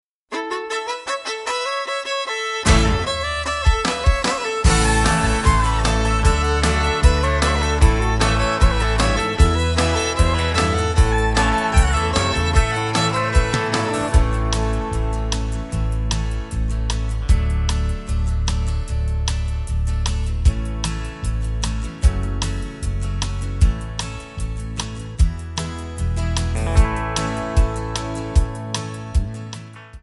MPEG 1 Layer 3 (Stereo)
Backing track Karaoke
Country, 1990s